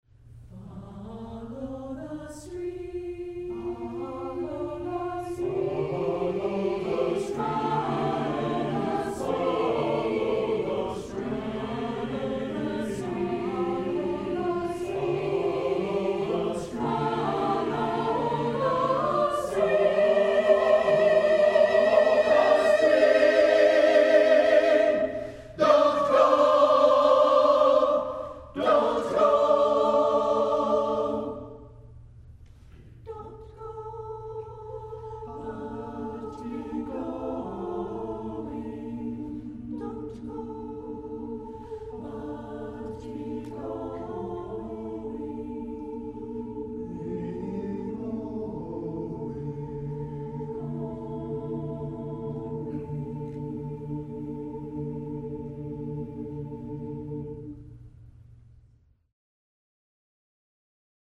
Eight Zen-like poems for SATB (and piano in some of them.)